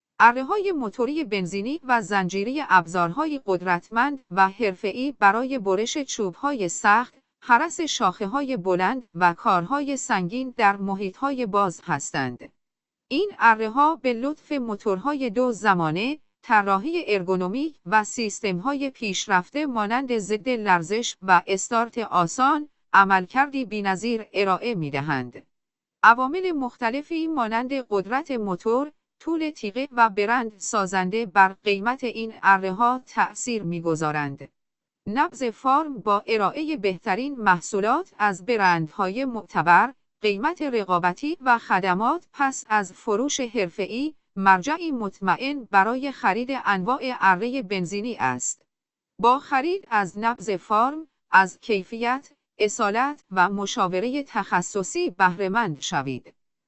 اره موتوری بنزینی
Gasoline-chainsaw.ogg